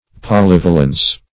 polyvalence - definition of polyvalence - synonyms, pronunciation, spelling from Free Dictionary